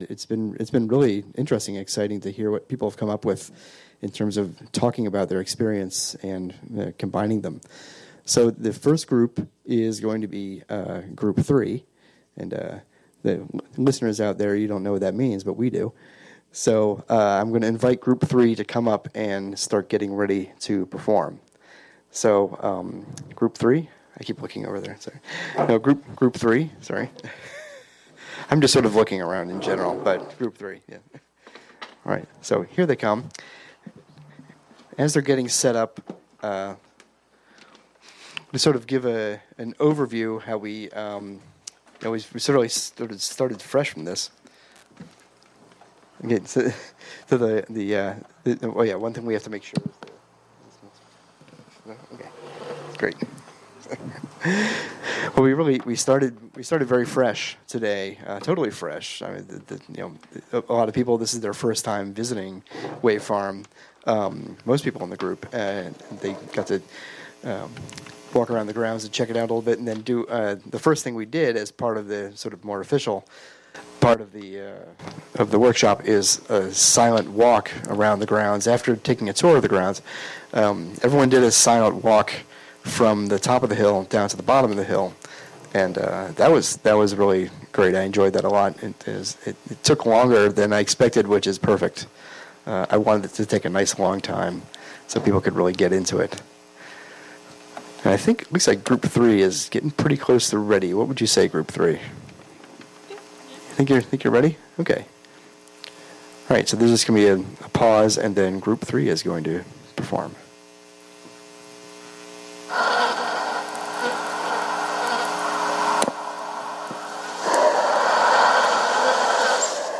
Wave Farm + WGXC Acra Studio